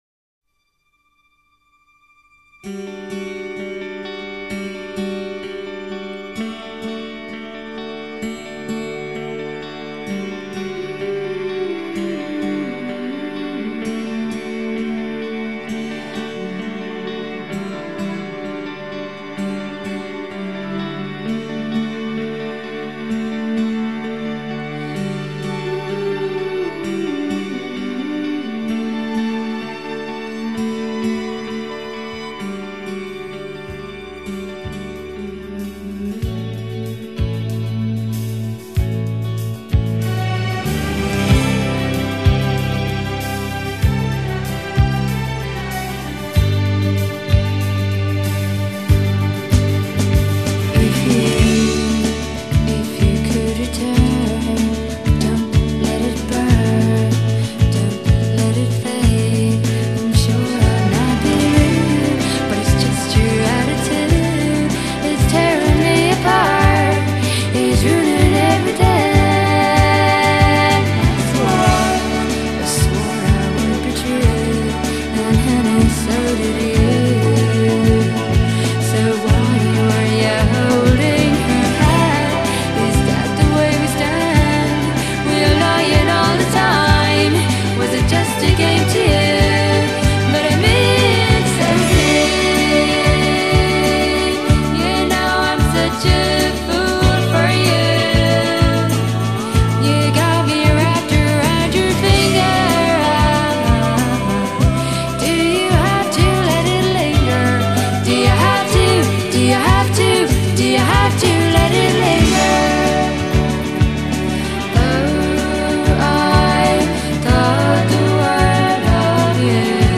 爱尔兰的流行乐队